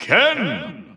The announcer saying Ken's name in English and Japanese releases of Super Smash Bros. Ultimate.
Ken_English_Announcer_SSBU.wav